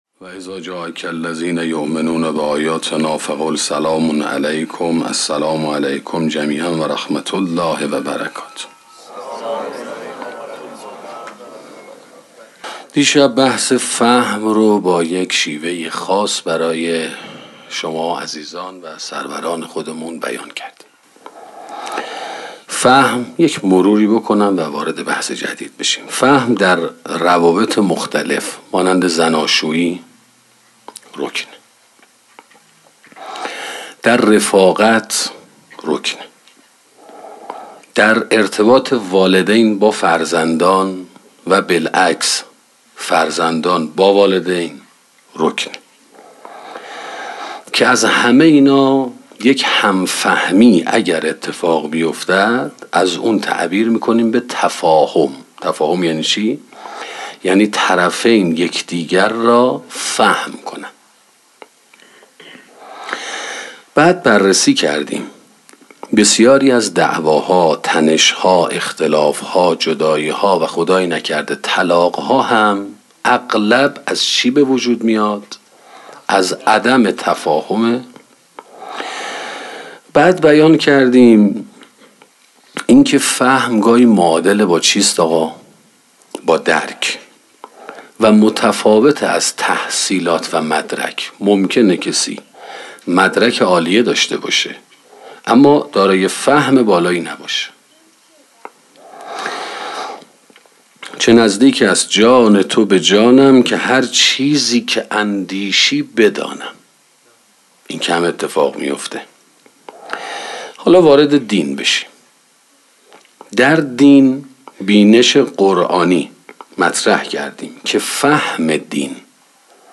سخنرانی خانواده موفق 2